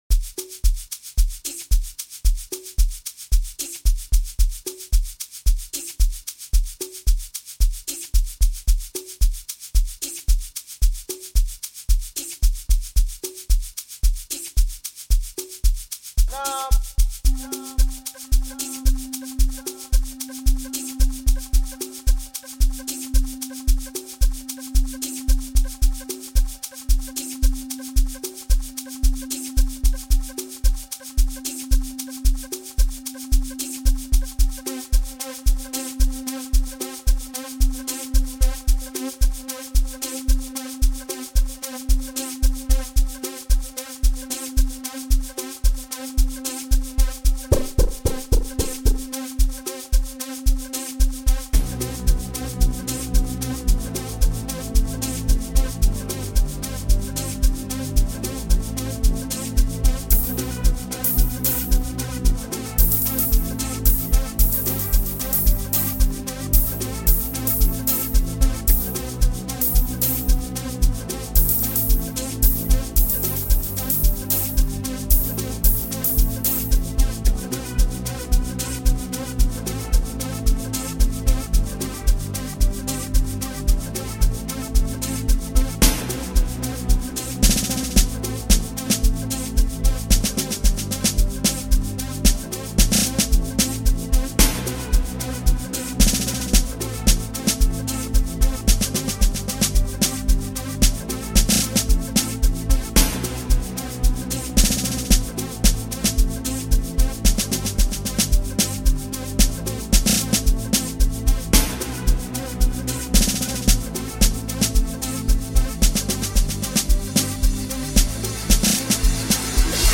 Maplanka music
Amapiano